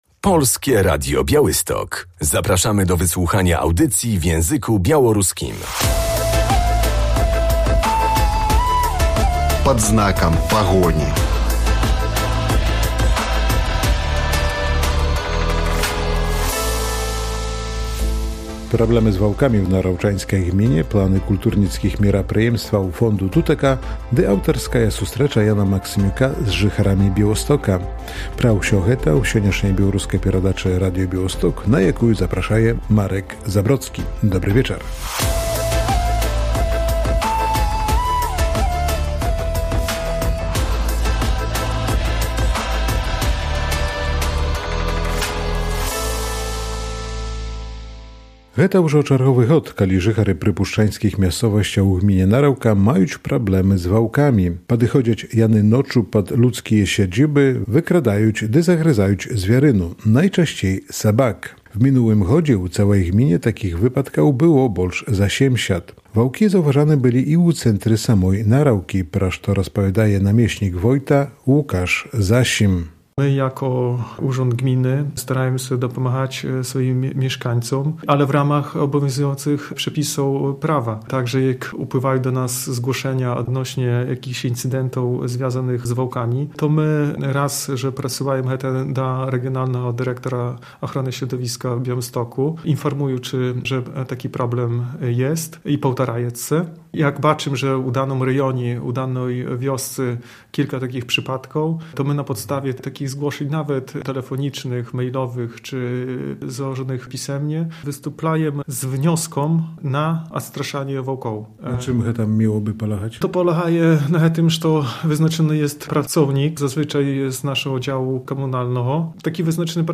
O problemach z wilkami rozmawiamy dziś z zastępcą wójta Narewki – Łukaszem Zasimem.